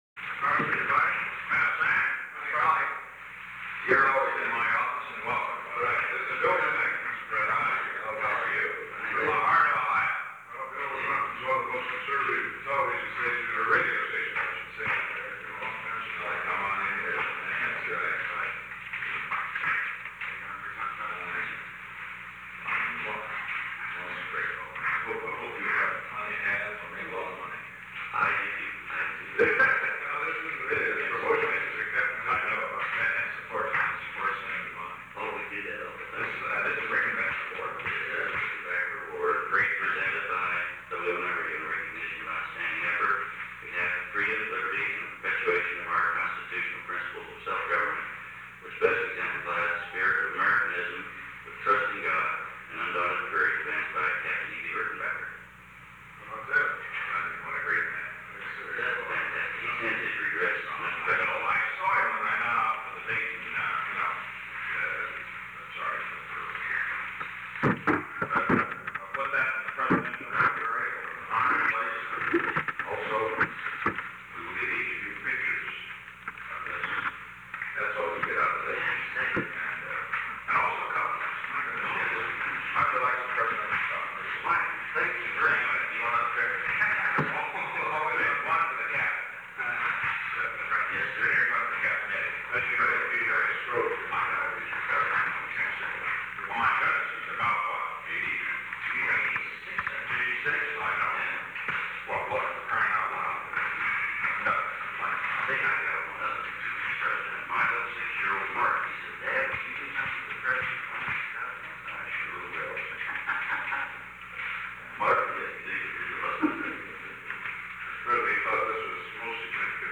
Secret White House Tapes
Location: Oval Office